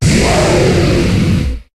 Cri de Volcanion dans Pokémon HOME.